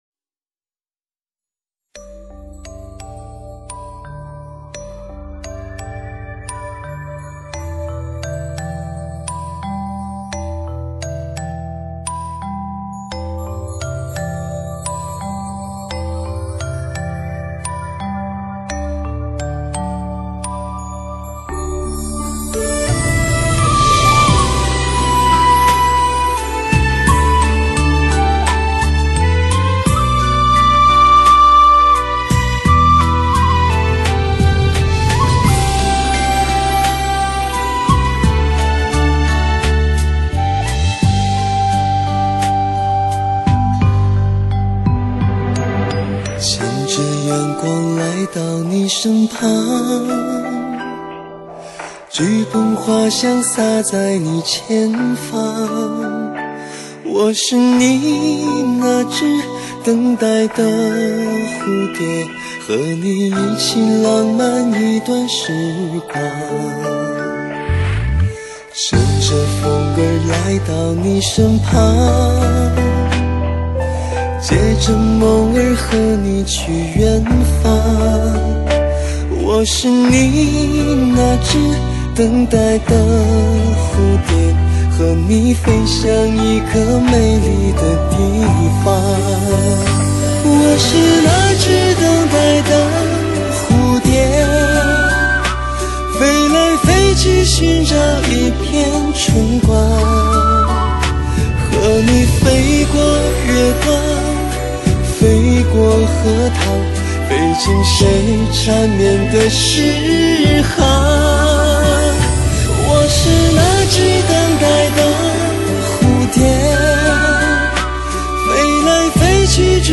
吉他
笛子